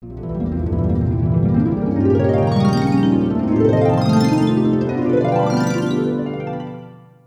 Harfen Jingles für Wundervolle Sequenzen.